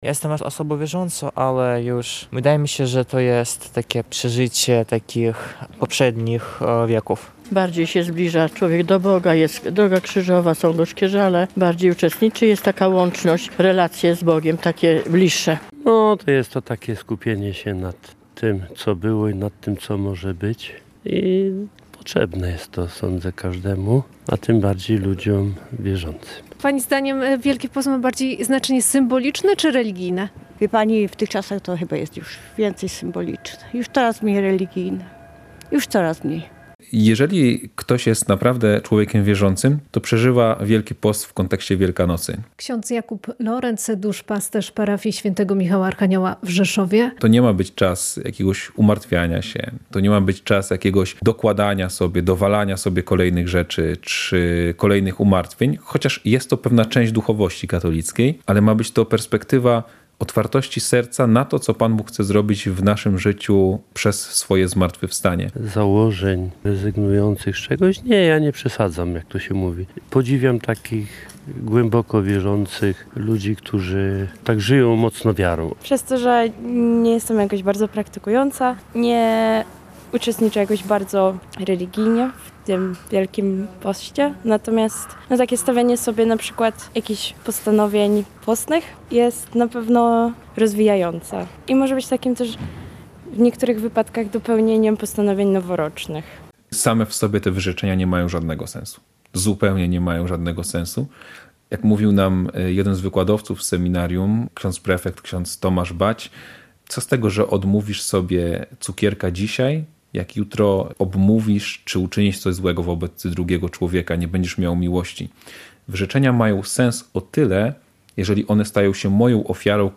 Relacje reporterskie